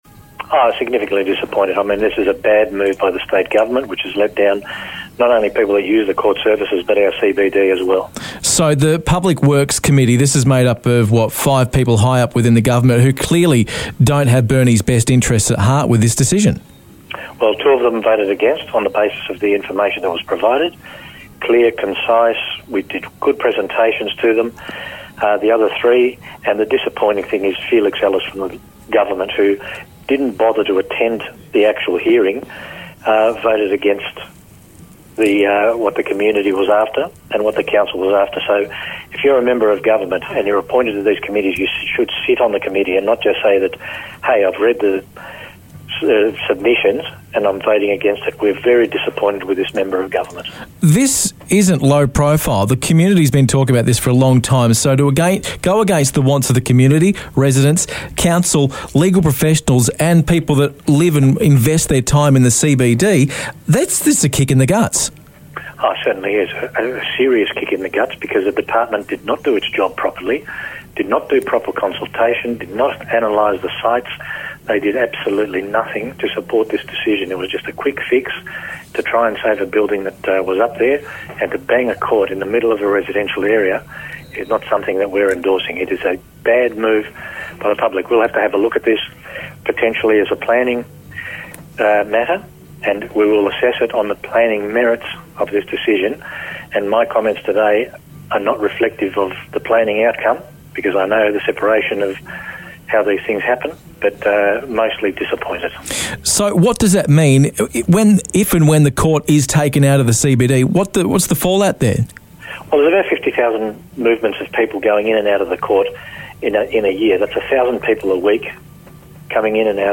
Headliner Embed Embed code See more options Share Facebook X Subscribe Burnie City Council is disappointed to hear that the Public Works Committee decided in a majority decision to approve the State Government’s plan to move the Burnie courthouse from the central business district (CBD) to the old University campus on Mooreville Road. Mayor Steve Kons speaks on The Home Run.